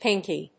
/ˈpɪŋki(米国英語), ˈpɪŋki:(英国英語)/
音節pink･y発音記号・読み方pɪ́ŋki